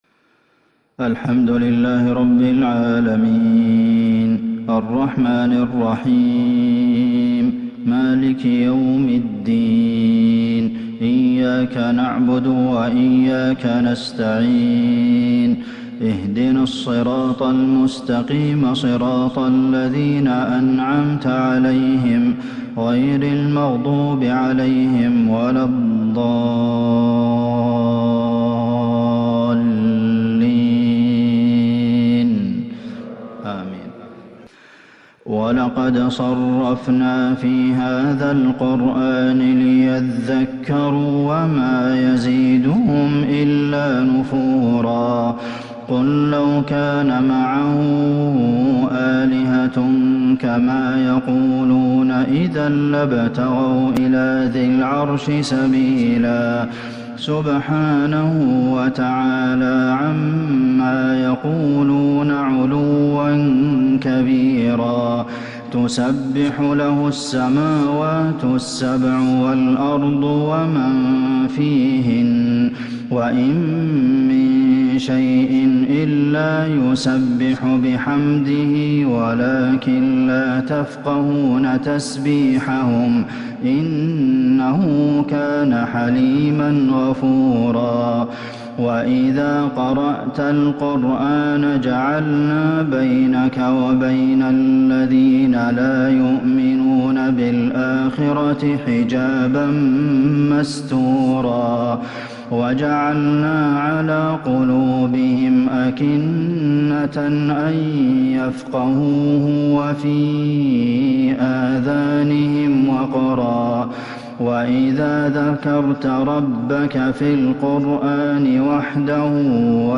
عشاء الأربعاء 7-6-1442هـ من سورة الإسراء | Isha prayer from Surah Al-Isrā’ 20/1/2021 > 1442 🕌 > الفروض - تلاوات الحرمين